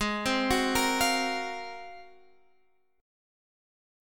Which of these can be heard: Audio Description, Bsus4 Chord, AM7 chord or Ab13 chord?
Ab13 chord